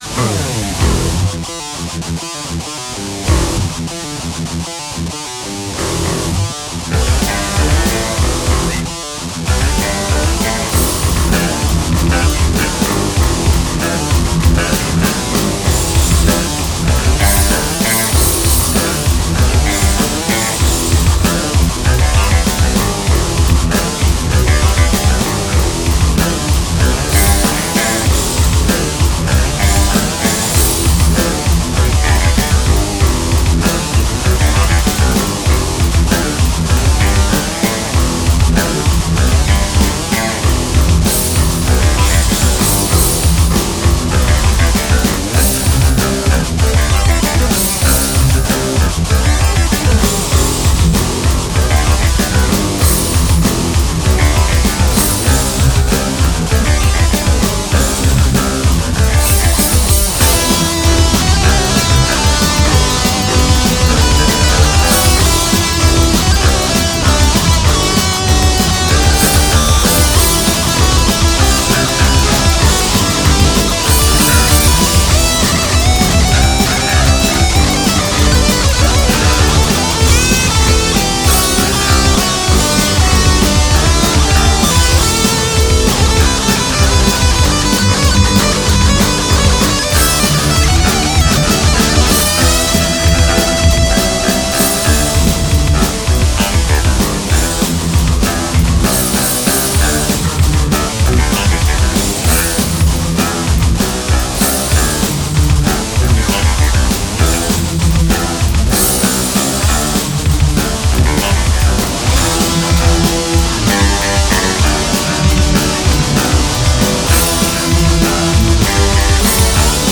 Untitled 15 started with a cool riff and all those layers on top.